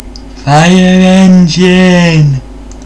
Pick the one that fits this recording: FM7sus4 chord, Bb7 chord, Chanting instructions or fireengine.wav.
fireengine.wav